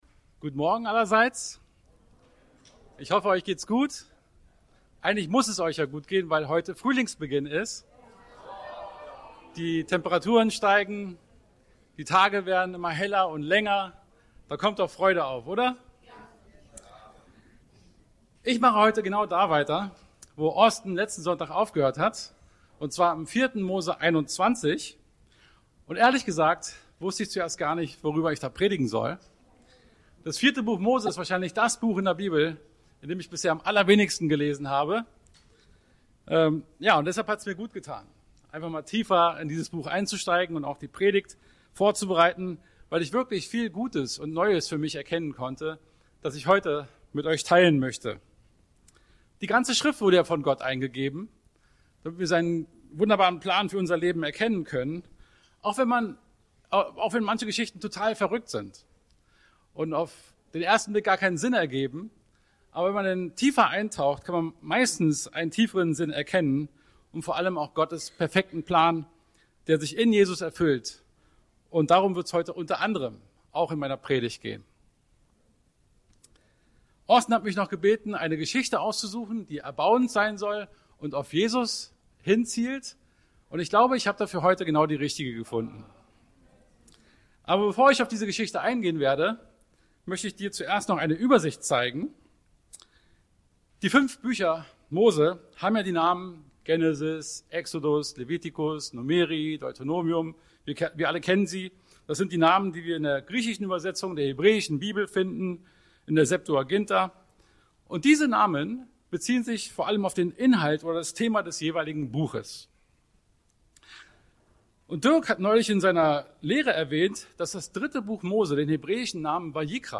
Gottesdienst Predigten